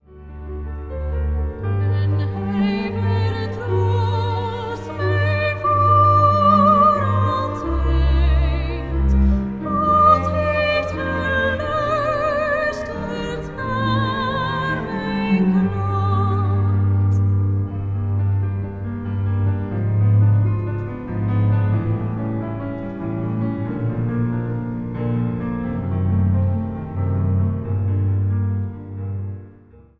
Cantates
dwarsfluit
tenor
sopraan
piano
orgel.
Zang | Gemengd koor